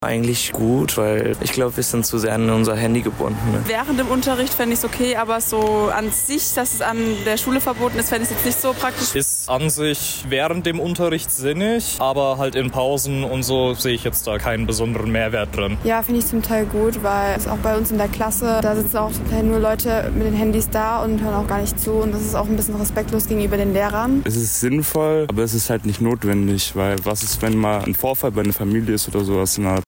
Umfrage-SWHandyverbot-Wie-faendet-ihr-es-wenn-Handys-an-Schulen-verboten-waeren.mp3